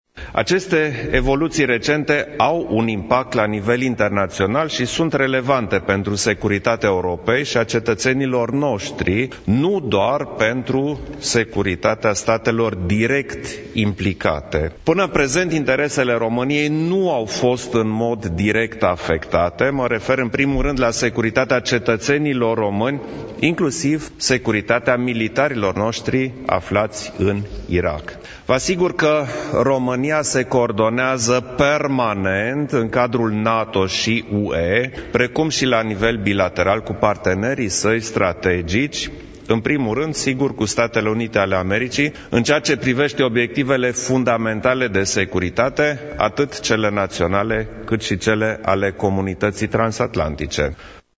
Președintele României, Klaus Iohannis, a declarant în urmă cu puțin timp că situația de Securitate din Orientul Mijlociu s-a agravat în ultimele zile, iar amplificarea tensiunilor nu este în intereseul comunității internaționale.